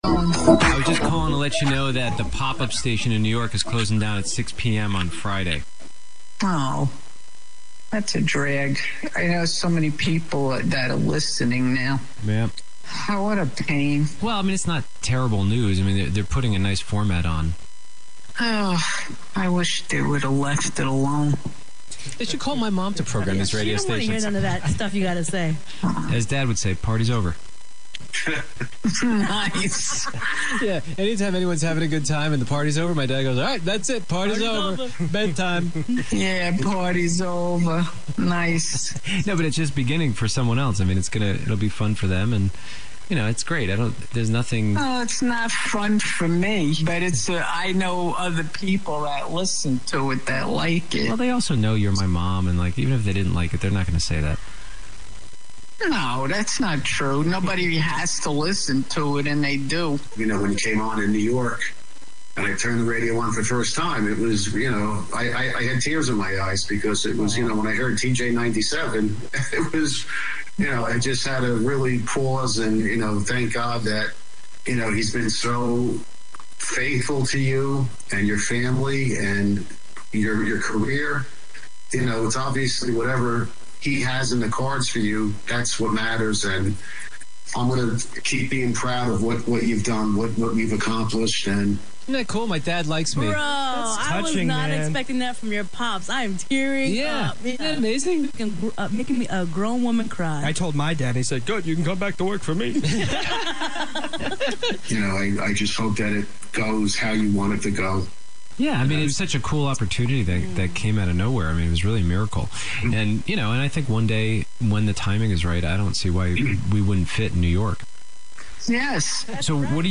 Previous Format: Hot AC “TJ 98.7
New Format: Bilingual AC “La Exitosa 98.7